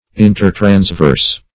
Search Result for " intertransverse" : The Collaborative International Dictionary of English v.0.48: Intertransverse \In`ter*trans*verse"\, a. Between the transverse processes of the vertebr[ae].
intertransverse.mp3